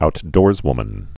(out-dôrzwmən)